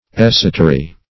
Esotery \Es"o*ter*y\, n.